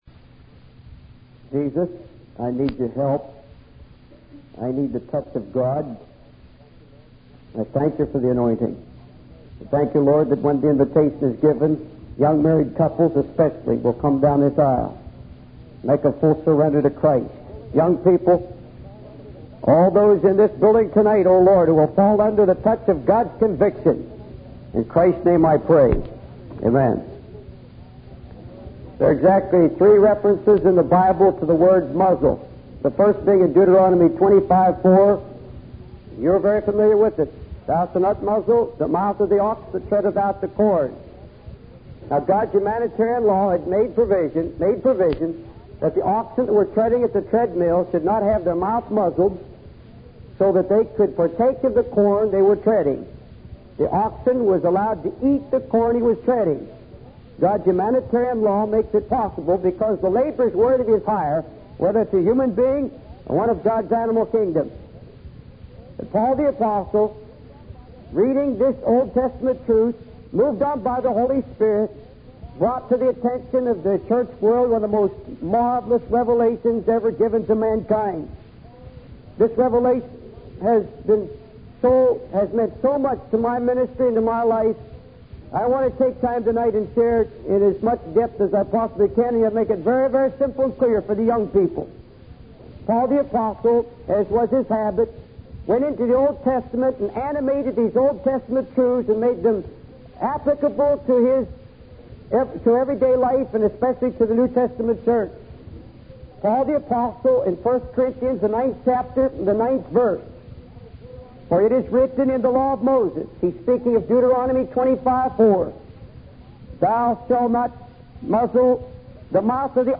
In this sermon, the speaker shares a personal story about neglecting his children and realizing the importance of giving them attention. He then relates this to the parable of the talents in the Bible, where the master returns and calls for an accounting from his servants. The speaker emphasizes the importance of having a right relationship with the Lord and being productive and fruitful in our service to Him.